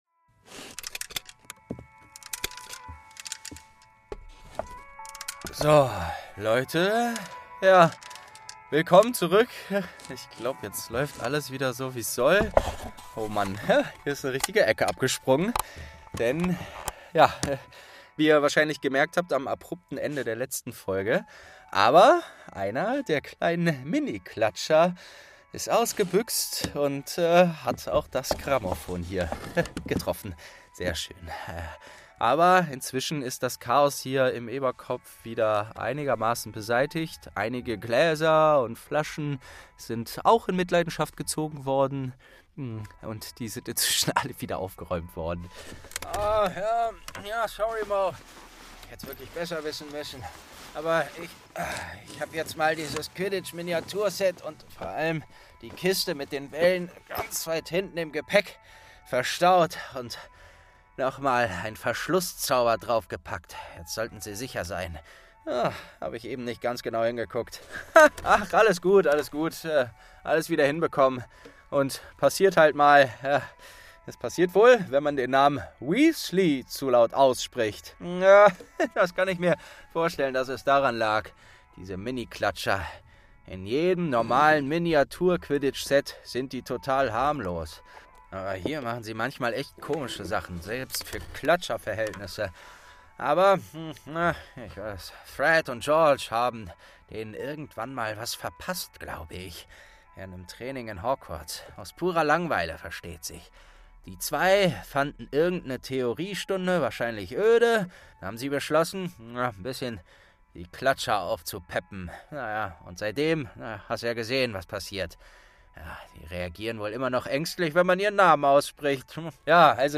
28. Oliver Wood| St. 2 ~ Geschichten aus dem Eberkopf - Ein Harry Potter Hörspiel-Podcast Podcast